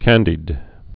(kăndēd)